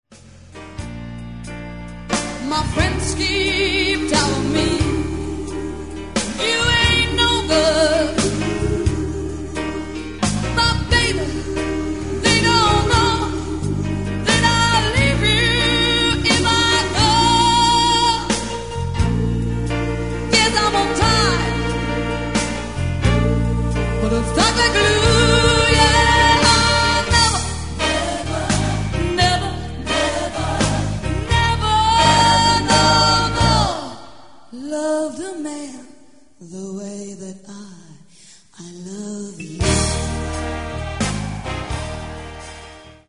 CD-LIVE